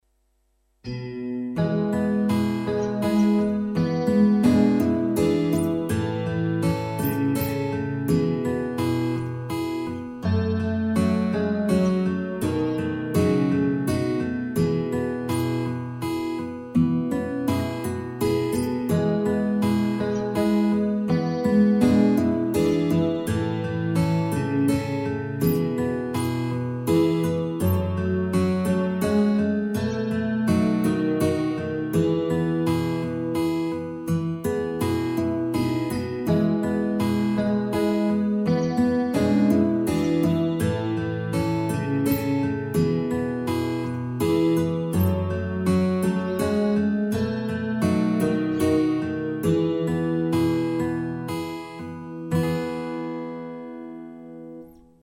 Chitara